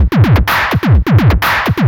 DS 127-BPM A5.wav